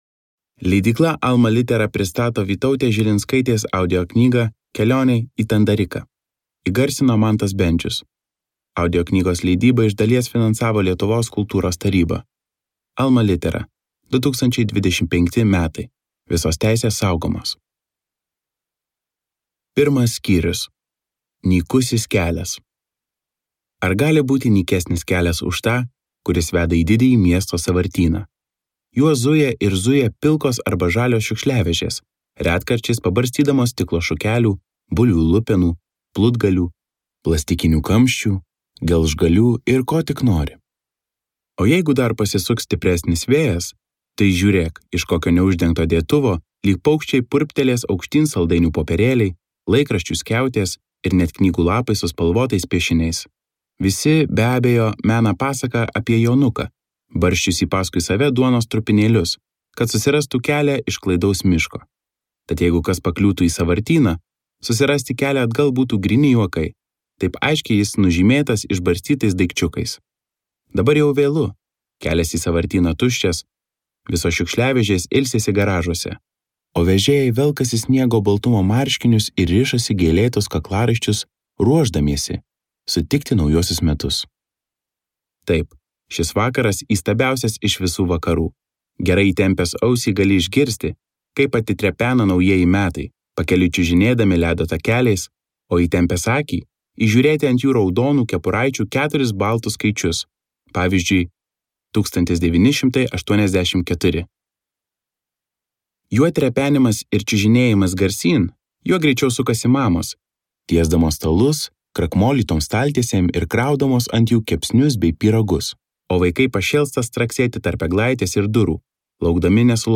Audioknygos leidybą iš dalies finansavo Lietuvos kultūros taryba.